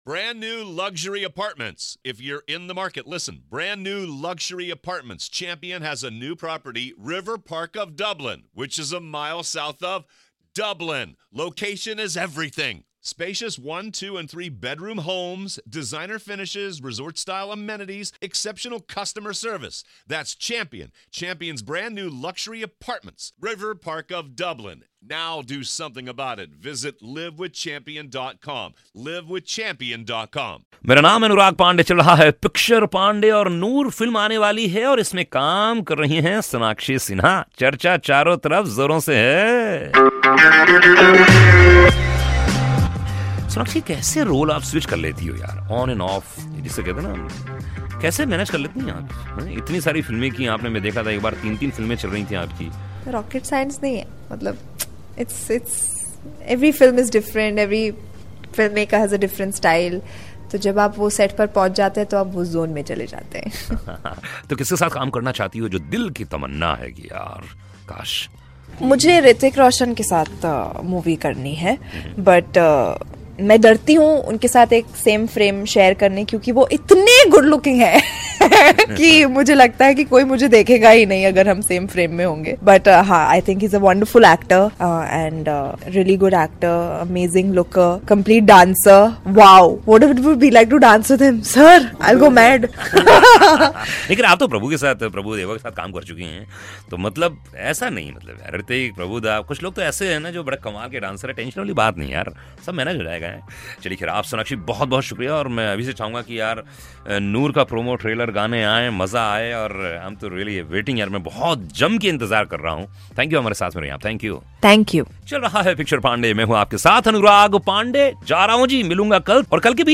Interview of Movie Noor -Link 4